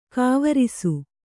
♪ kāvarisu